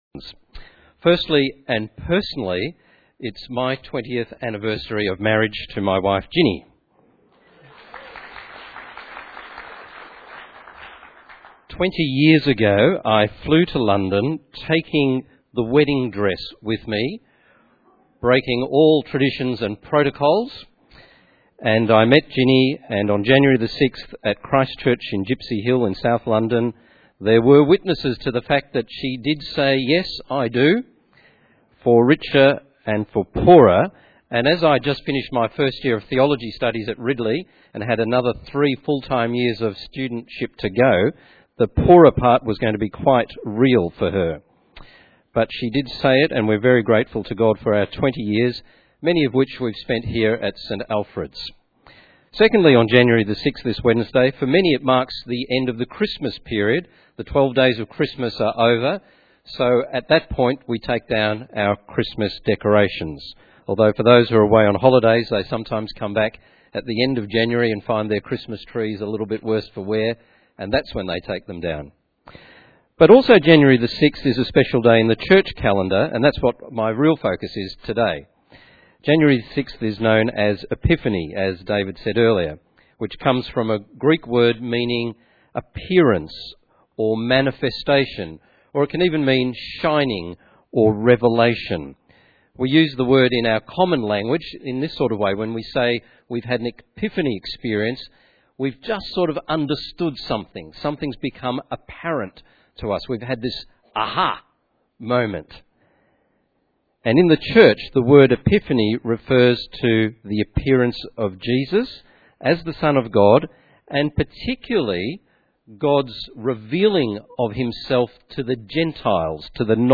Sermons | St Alfred's Anglican Church